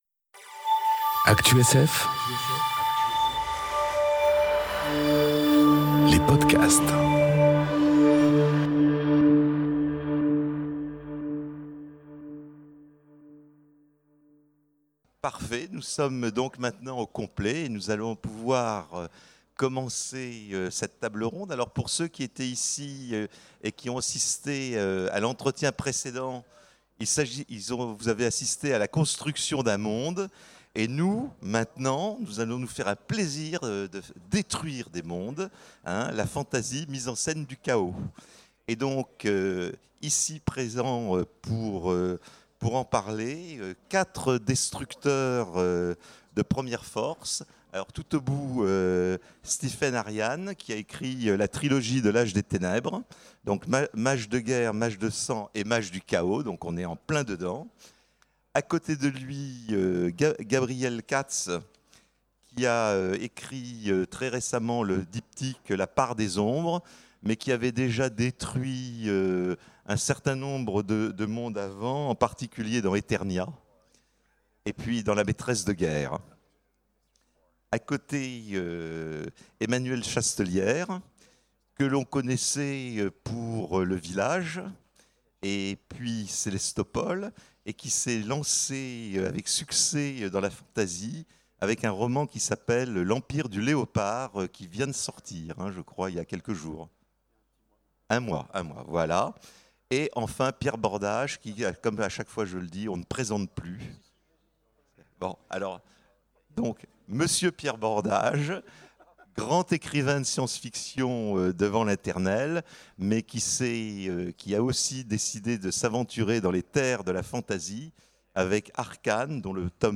Conférence Les destructeurs de monde... La fantasy, mise en scène du chaos ? enregistrée aux Imaginales 2018